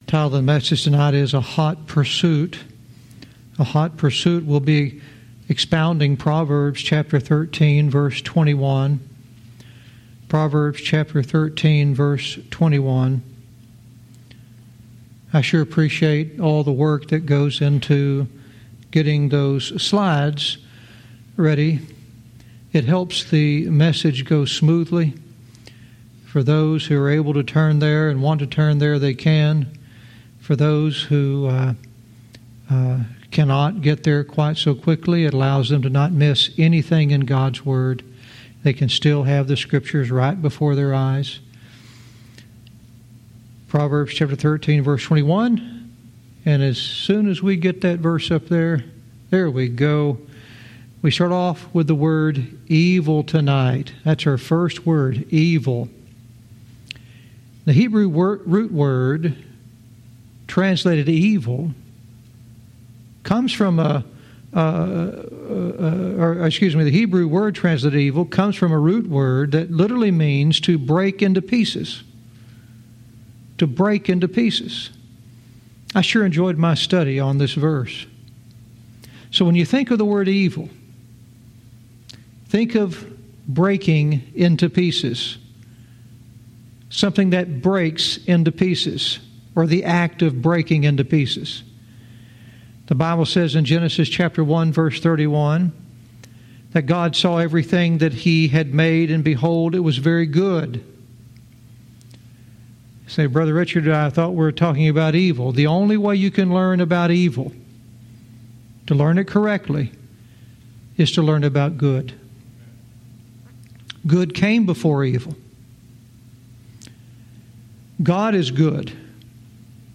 Verse by verse teaching - Proverbs 13:21 "A Hot Pursuit"